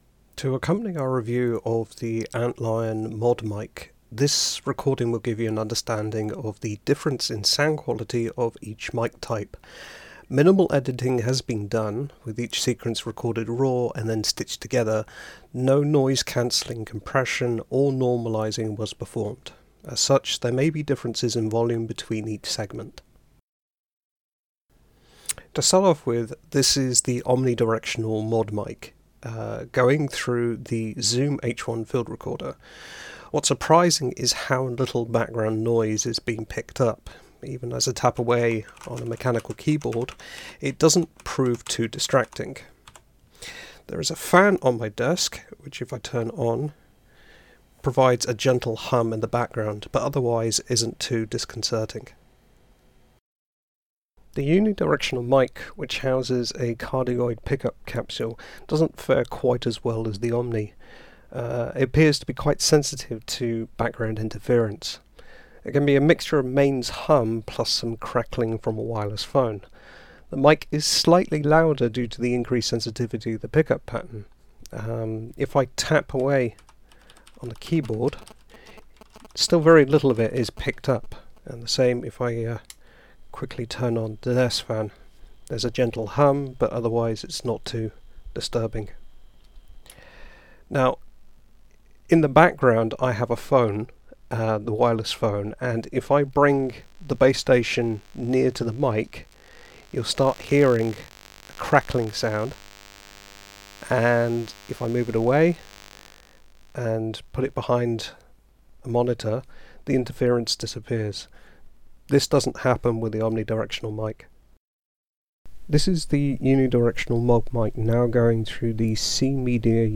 The two mics will be tested on two different recording inputs; the USB DAC Antlion provides on its website, as recommended to Mac users, as well as a Zoom H1 field recorder, as it provides a much better ADC, so you can hear what these mics are truly capable of.
Under testing, I found the biggest offender was a wireless land-line telephone, which produced a rather aggressive saw-wave sound. The mic also picked up some mains AC hum too.
The mic is more sensitive than the Omni, and does have a greater proximity effect on the voice (slightly more bass).
The clatter from the keyboard as I typed, the whistling of a fan, none of it was distracting, and my voice comes through clearly.
As you can hear in the recording, simply moving the phone base station away removed most of the interference. The examples above are not exactly fantastic, but Uni mic does isolate my voice a little better from the background (less background hiss and echo), with a slight increase in bass response.
ModMic-Audio-Comparison-Between-The-Omni-Uni-Mics.mp3